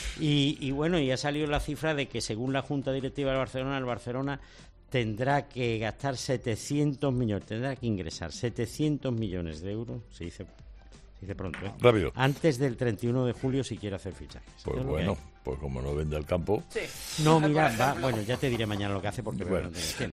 Con cierta ironía, pero señalando claramente las difíciles medidas que tendrá que tomar el Barcelona, Carlos Herrera le da en el audio anterior una clave sobre cómo conseguir dinero rápido al Fútbol Club Barcelona, cifrando la entidad en 700 millones de euros la necesidad que tiene de realizar ingresos antes del último día del mes de julio.